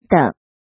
怎么读
děng